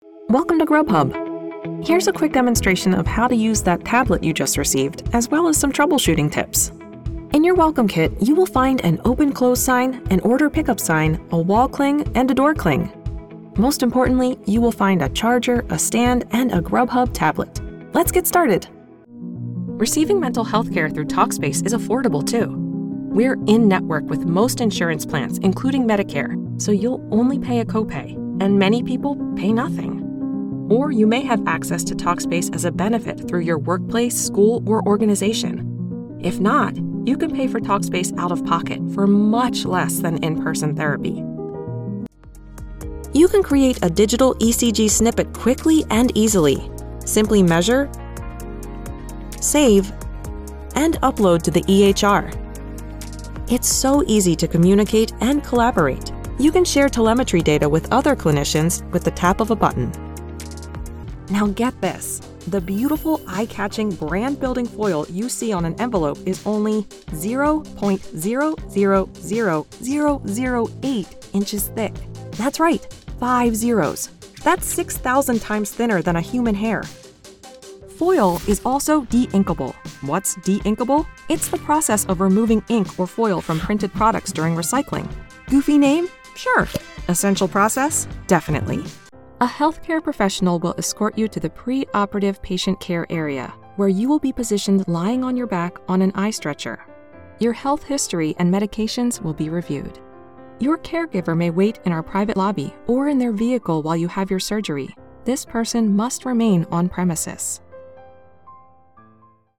From commercials and explainer videos to e-learning, promos, and narration, I offer a warm, engaging sound designed to connect with your audience.
Explainer Demo